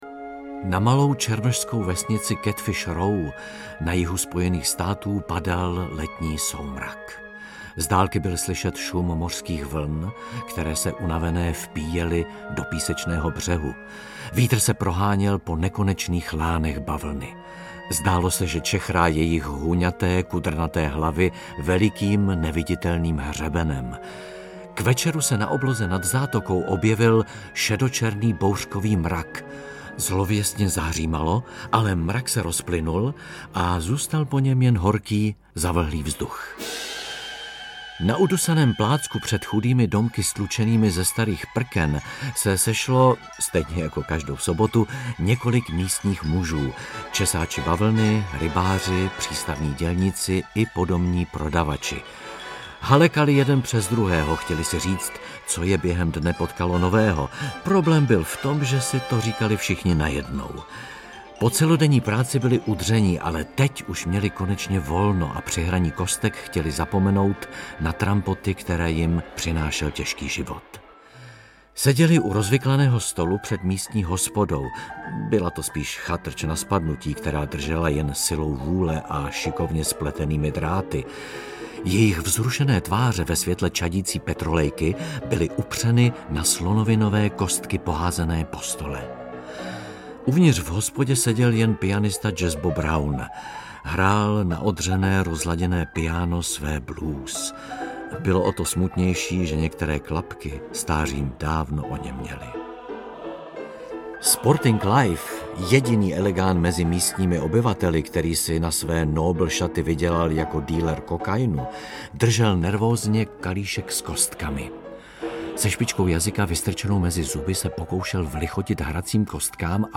Nebojte se klasiky! 24 George Gershwin: Porgy a Bess - George Gershwin - Audiokniha
• Čte: Bára Hrzánová, Tereza Dočkalová, Jan…